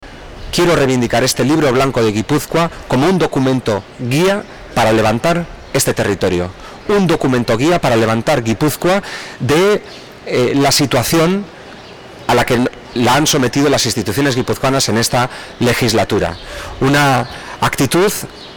El candidato socialista a Diputado General, Denis Itxaso, ha presentado oficialmente esta mañana en la Plaza de Gipuzkoa y frente a la Diputación Foral de Gipuzkoa